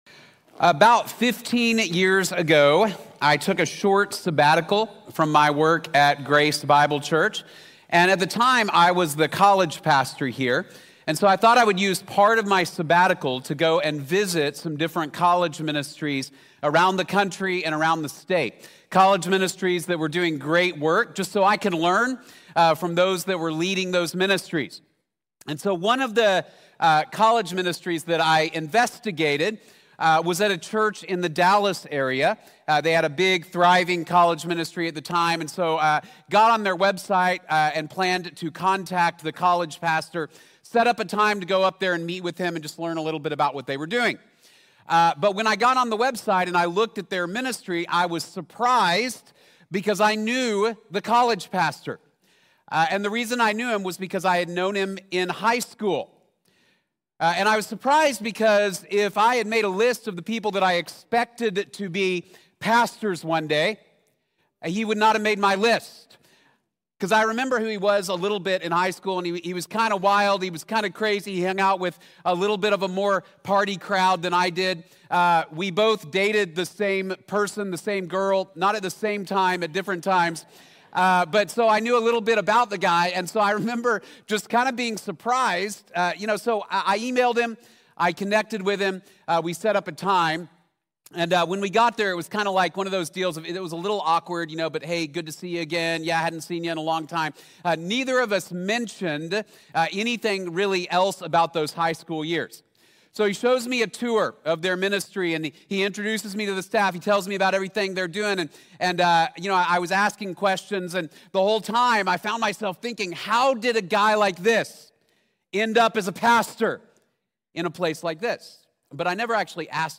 Lost and Found | Sermon | Grace Bible Church